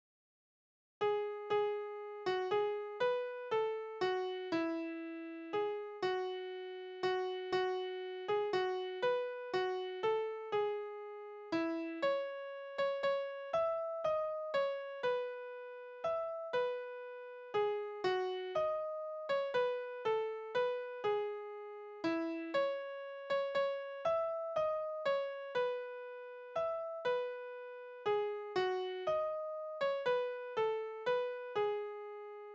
Naturlieder: Ich habe den Frühling gesehen
Tonart: E-Dur
Taktart: 3/4
Tonumfang: Oktave
Besetzung: vokal